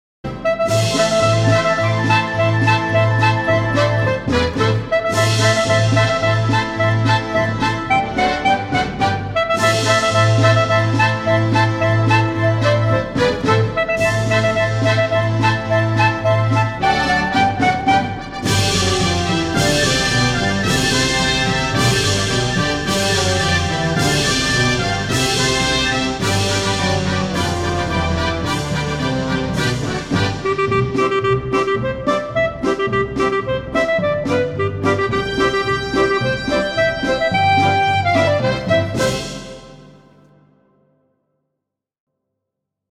Genre: Classical.